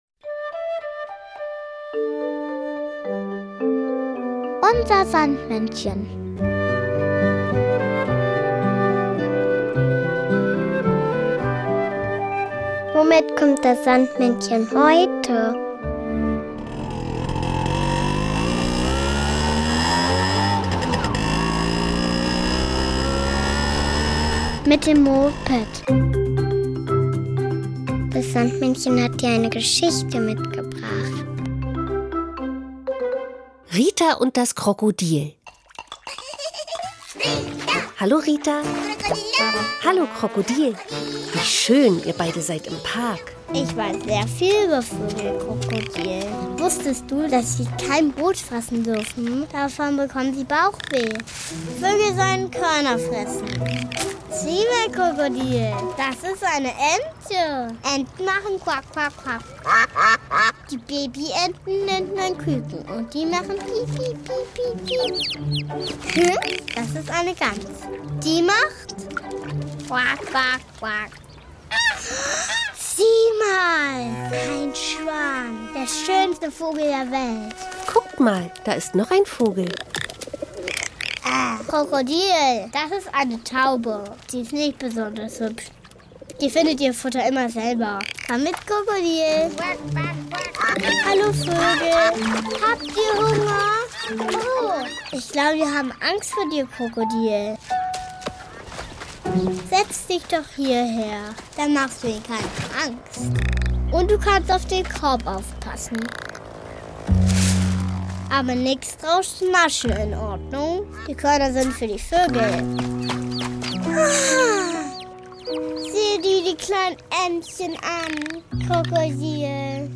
sondern auch noch das Kinderlied "Vogelschmaus" von Hexe